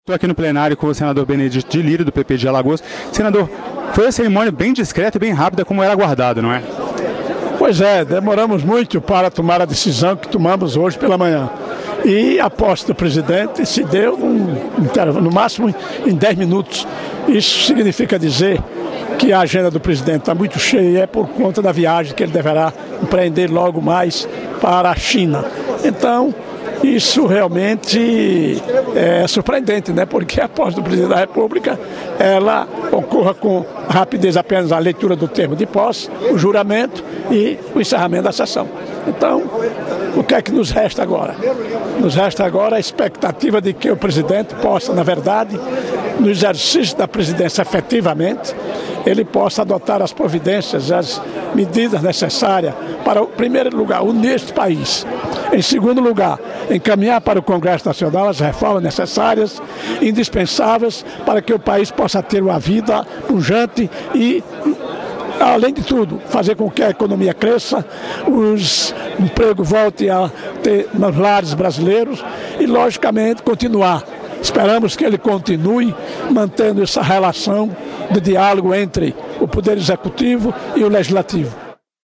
O senador Benedito de Lira (PP-AL) disse esperar que o presidente Michel Temer adote as medidas necessárias para unir o país e encaminhe ao Congresso as reformas indispensáveis para a economia voltar a crescer. Em entrevista